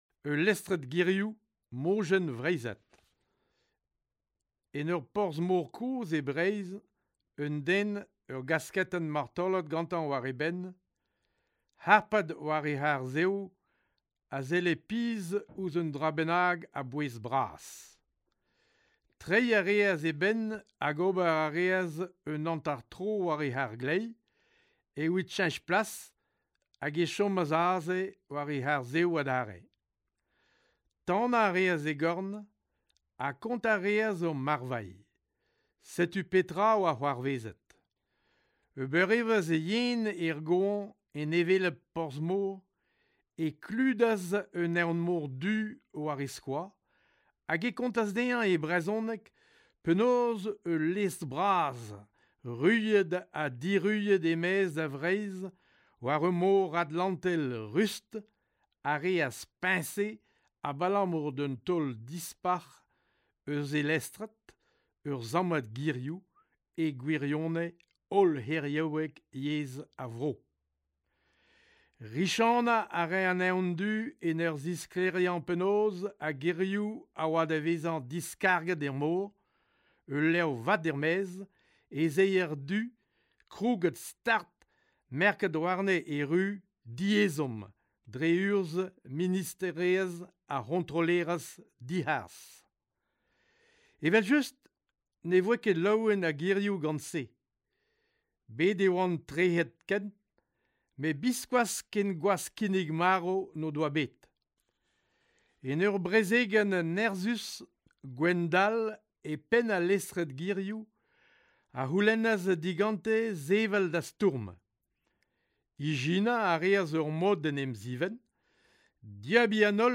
recite his translation of it into his native Breton in ringing tones that are somehow both gritty and embracing. You can almost feel the inhospitable roughness of rock on your skin and taste acrid brine, halfway through the Breton version.